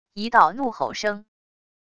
一道怒吼声wav音频